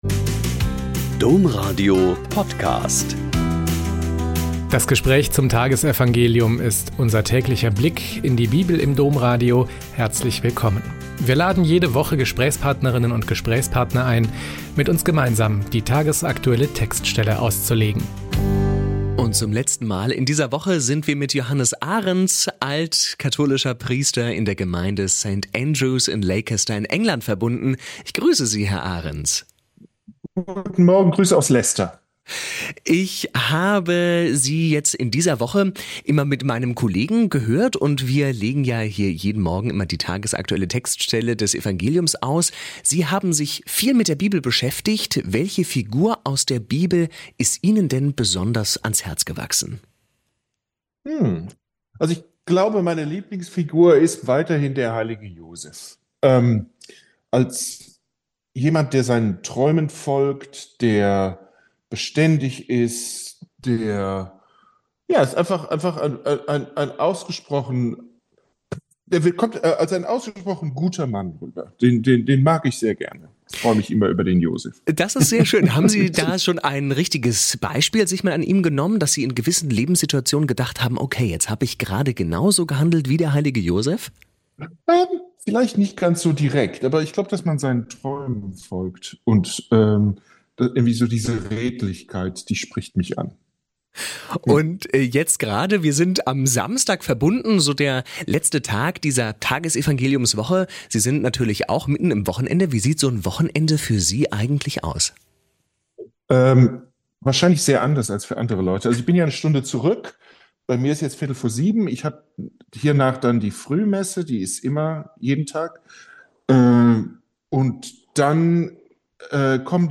Mk 10,13-16 - Gespräch